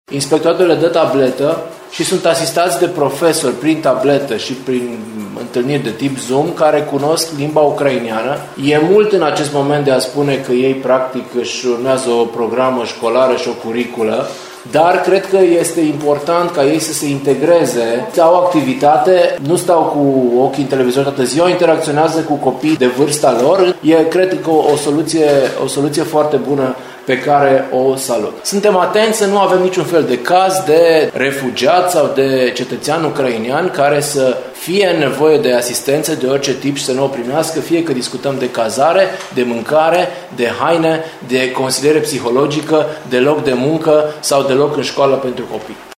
Prefectul a mai anunțat că 10 copii ucraineni cer, în fiecare zi, să fie primiți la o școală din Timiș, fiind deja depuse astfel de cereri.